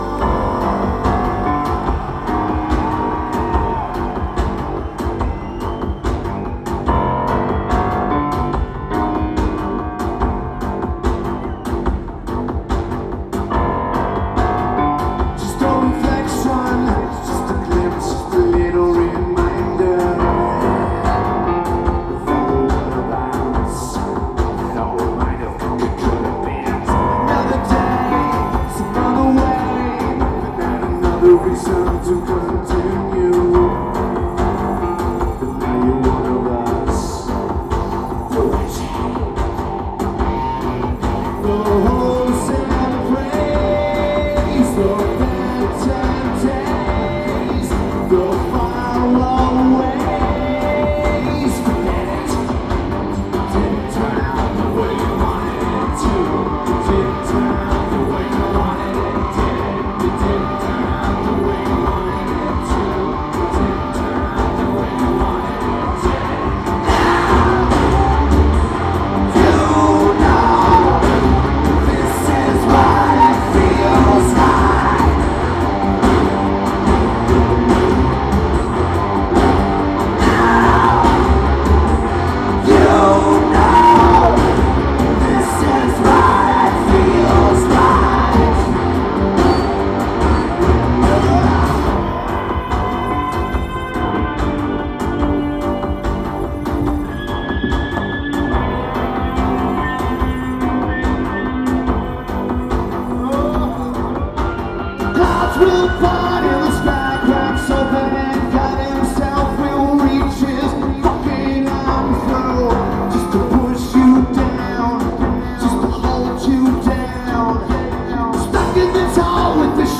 Le Zénith
Lineage: Audio - AUD (CSBs + Sony TCD-D8)
A good audience tape.